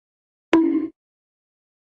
دانلود آهنگ چماق 1 از افکت صوتی انسان و موجودات زنده
دانلود صدای چماق 1 از ساعد نیوز با لینک مستقیم و کیفیت بالا
جلوه های صوتی